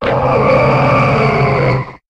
Cri de Wailord dans Pokémon HOME.